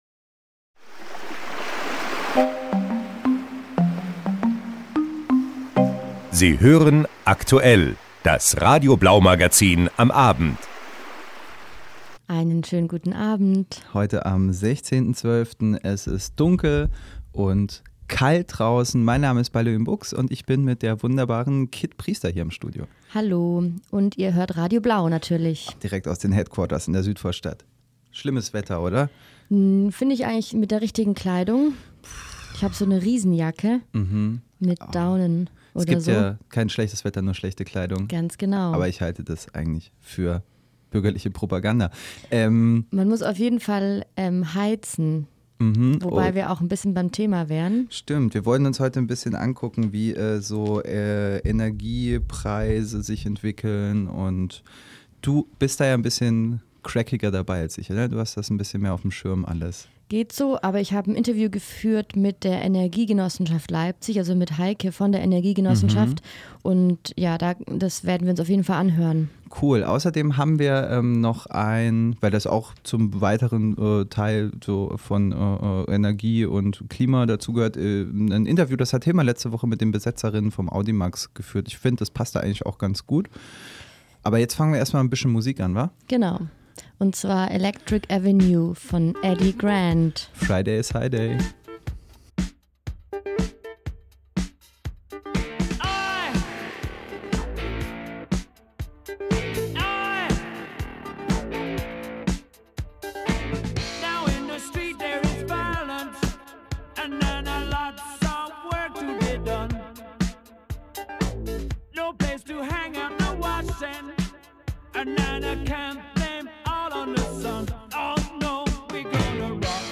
Die EGL im Interview mit Radio Blau
Das Interview beginnt bei Minute 27:22.